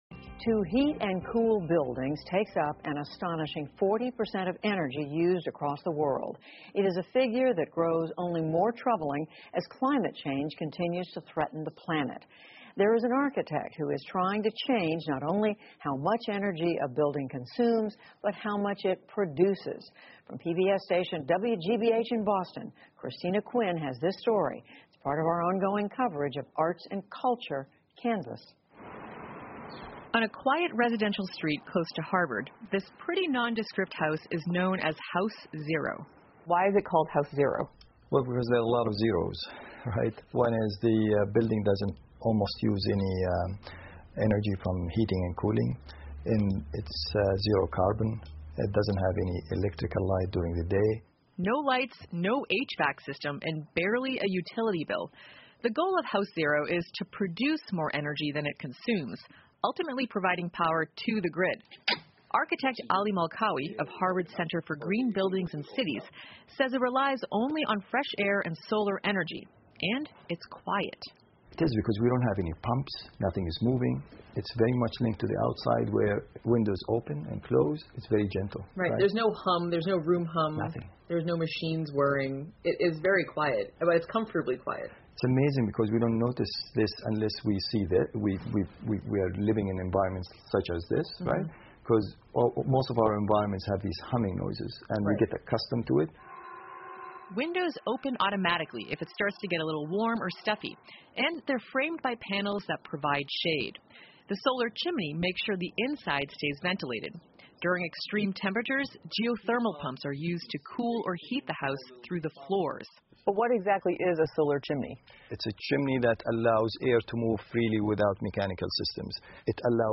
在线英语听力室PBS高端访谈:零消耗实验室的听力文件下载,本节目提供PBS高端访谈环境系列相关资料,内容包括访谈音频和文本字幕。